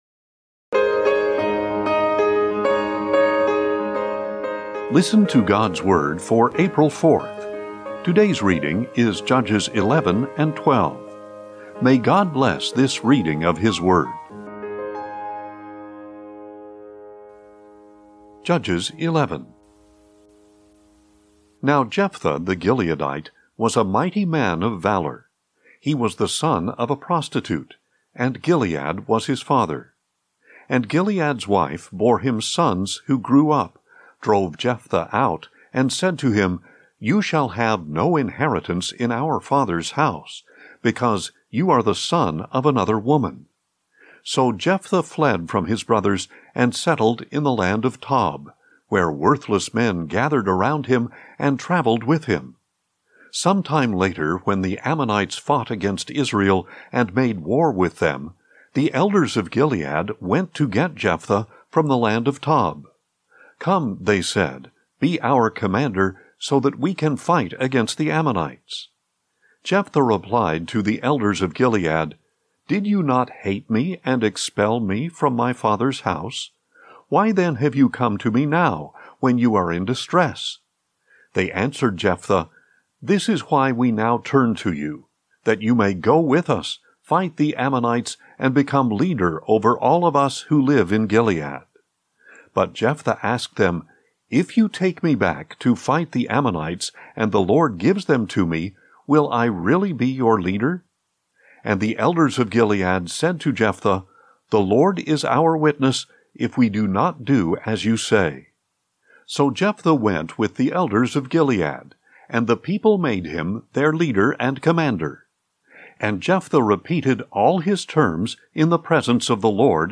Today's chronological Bible reading is Judges 11-12.